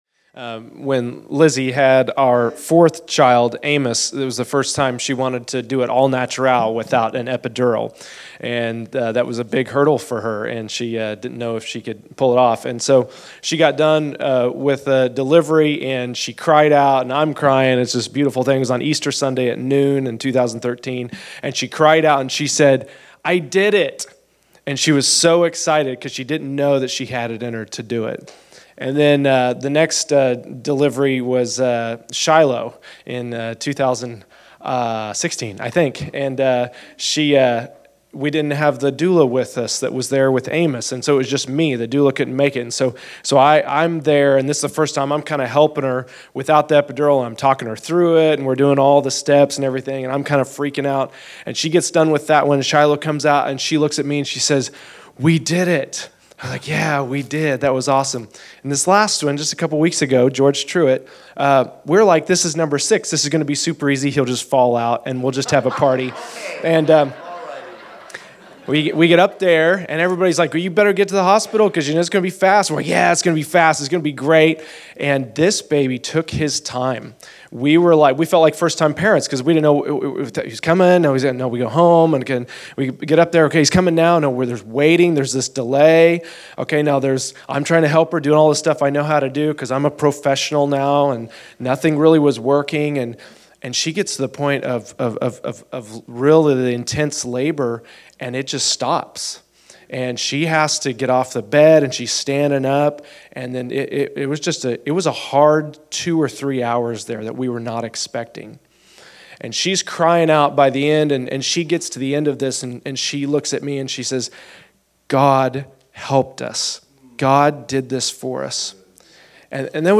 Teachings      |      Location: El Dorado